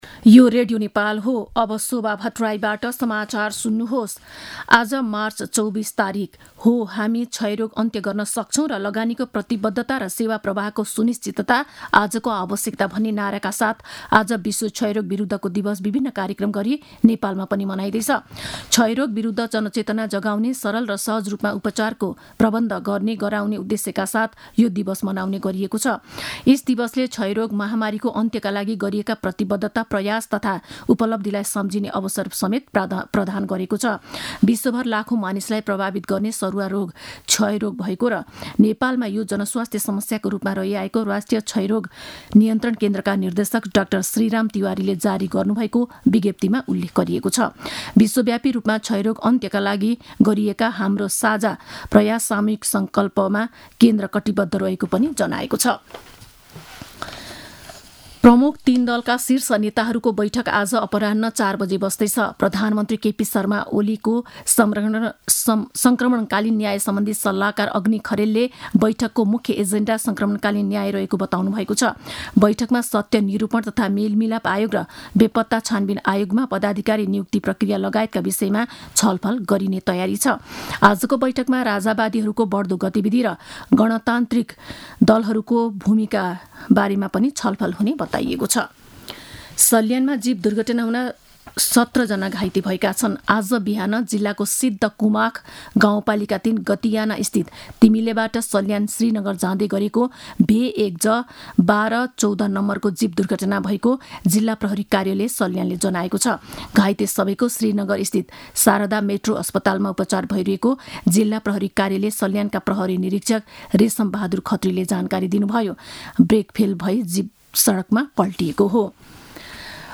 An online outlet of Nepal's national radio broadcaster
मध्यान्ह १२ बजेको नेपाली समाचार : ११ चैत , २०८१